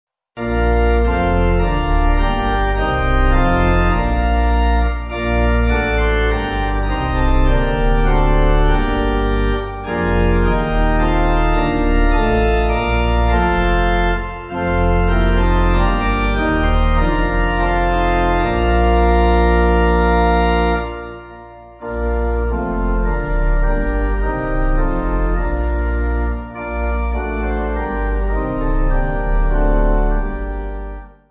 (CM)   4/Bb